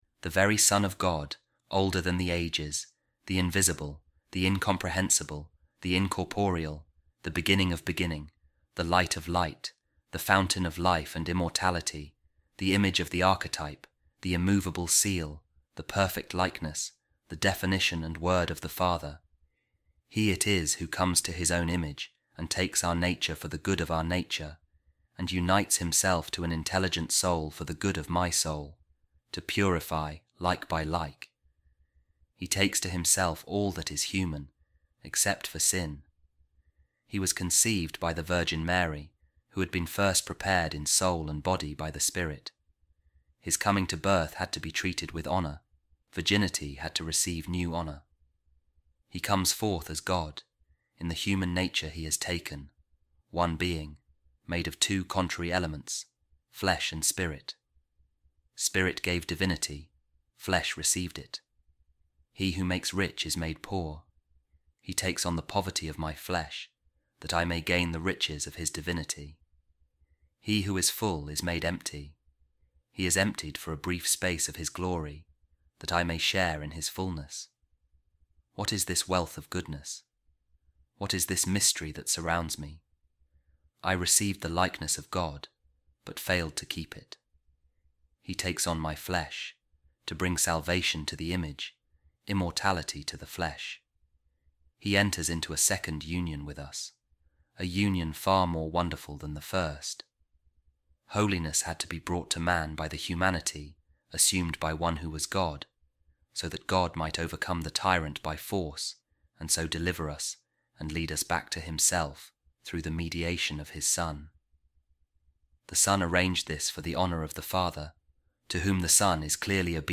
A Reading From The Orations Of Saint Gregory Nazianzen | The Wonder Of The Incarnation